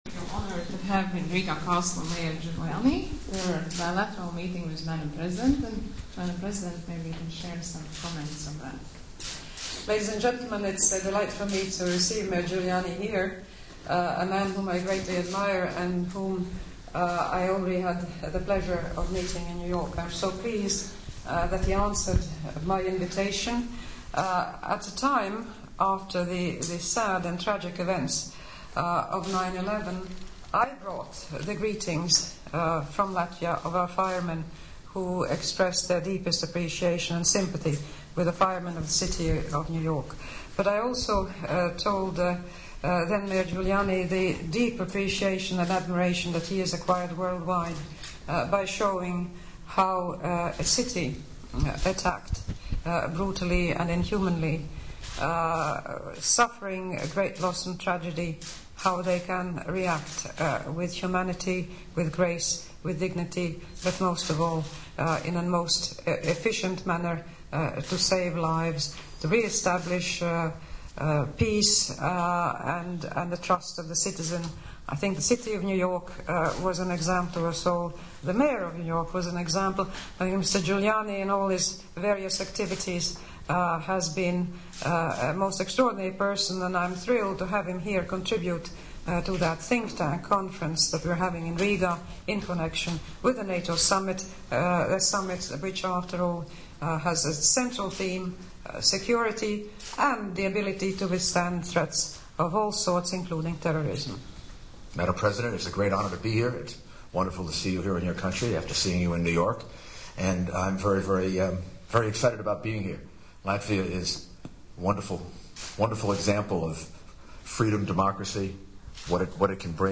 Valsts prezidente Vaira Vīķe-Freiberga šodien Rīgas pilī tikās ar bijušo Ņujorkas mēru Rūdolfu Džuliāni.
item_1003_VfreibergGuilianopressconf.mp3